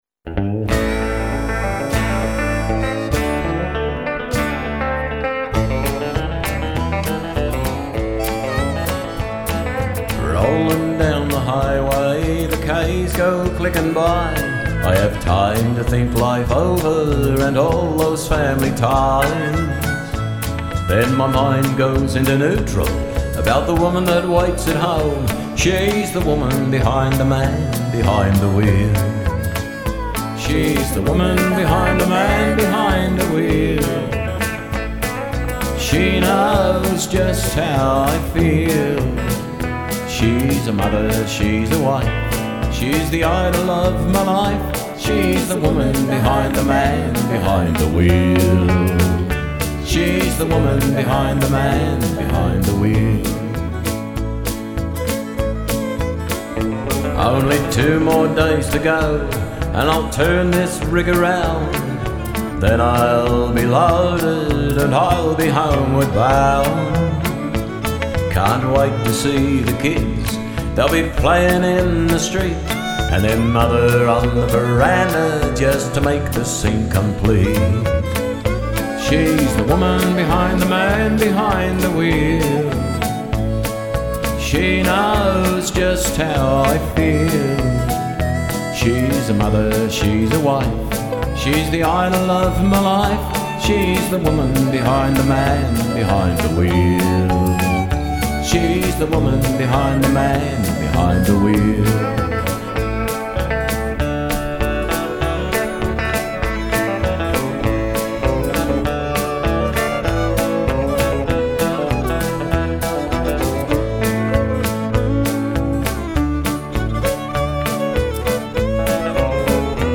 great upbeat radio single